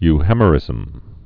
(y-hēmə-rĭzəm, -hĕmə-)